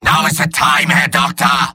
Robot-filtered lines from MvM. This is an audio clip from the game Team Fortress 2 .
{{AudioTF2}} Category:Medic Robot audio responses You cannot overwrite this file.